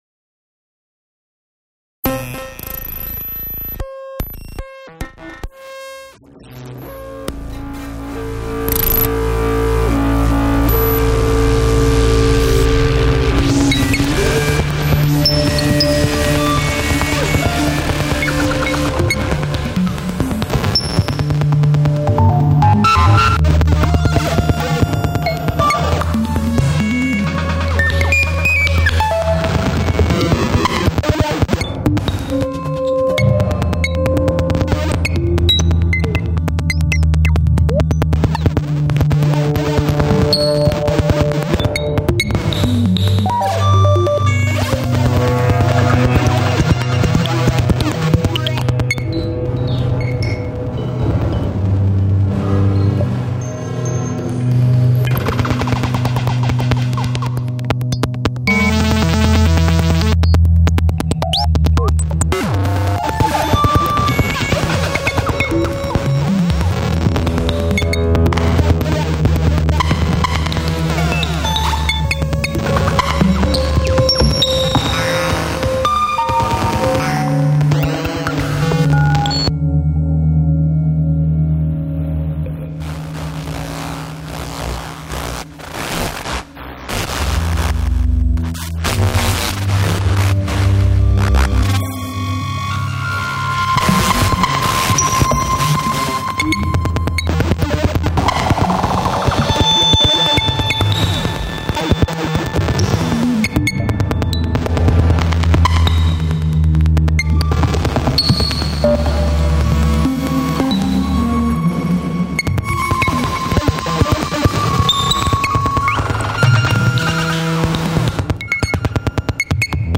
File under: Experimental / Avantgarde / DPOAE